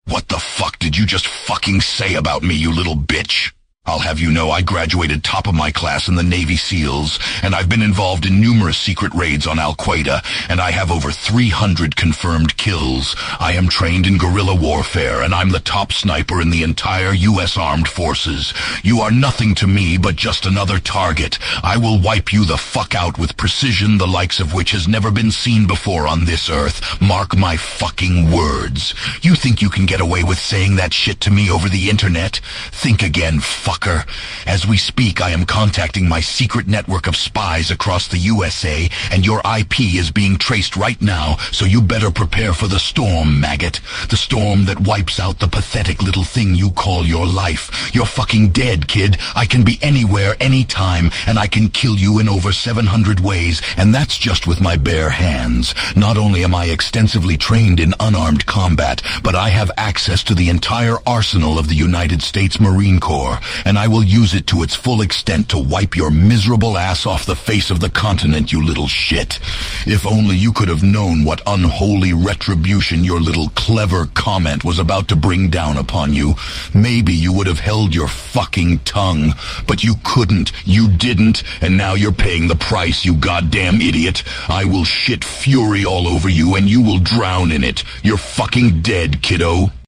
I Out of sheer boredom and curiosity, I asked # ChatGPT to write a little essay about # TrafficLights and how they differ from country-to-country. I then had an # AI version of yours truly read it, courtesy of # ElevenLabs , so here it is.